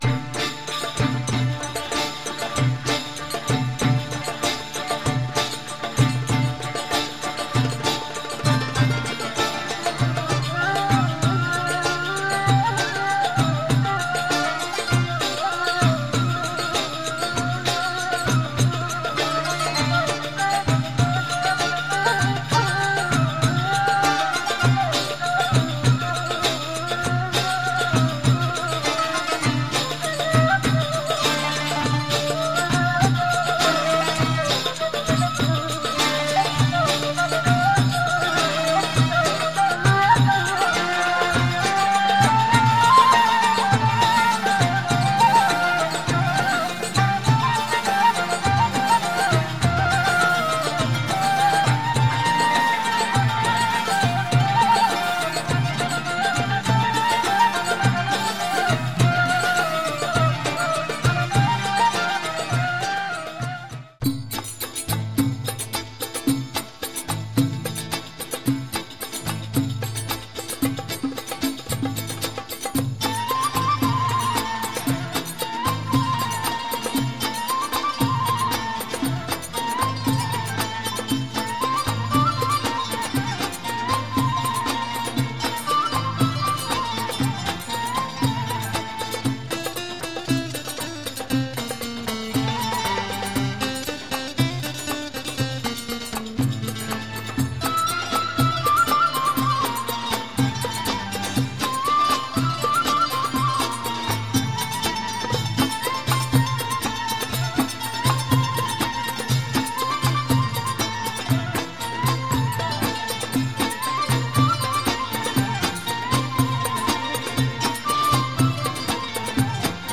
delivered by Armenian-Lebanese musicians.